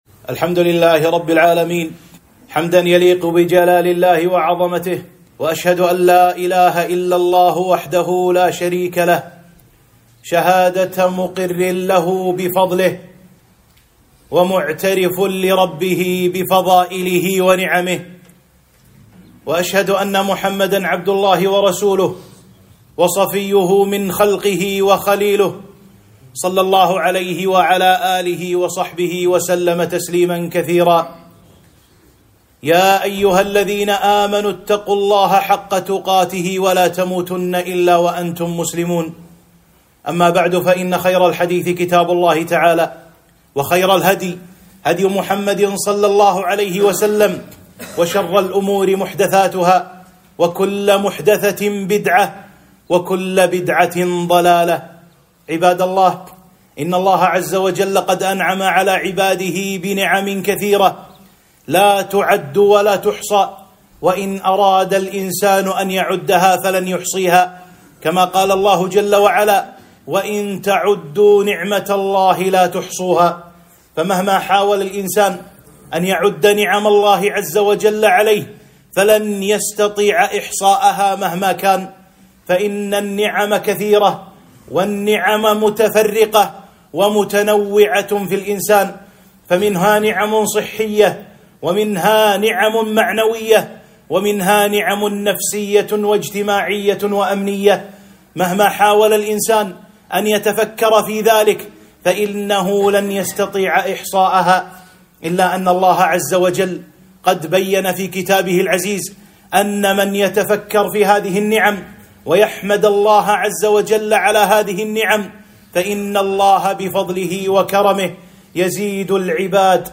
خطبة - (وإن تعدوا نعمت الله لا تحصوها )